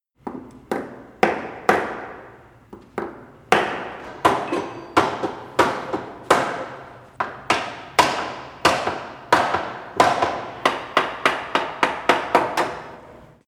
Рингтон Звук удара обычным молотком
Звуки на звонок